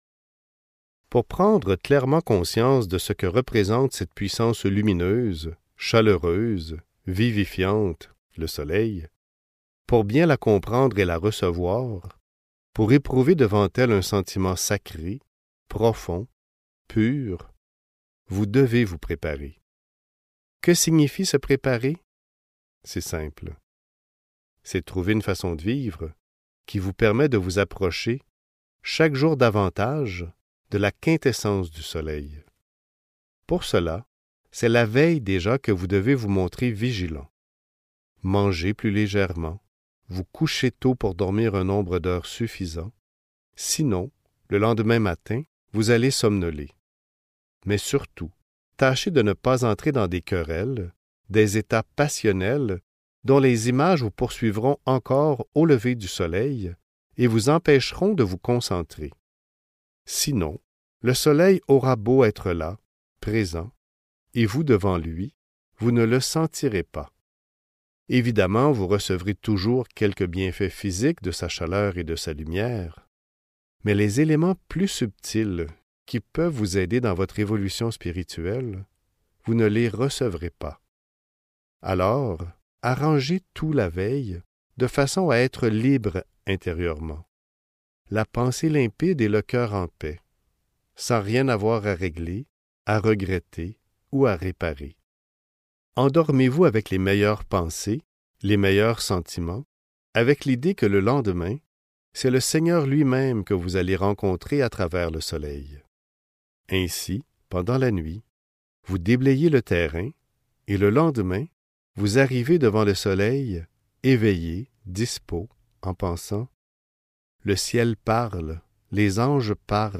Méditations au lever du soleil (Livre audio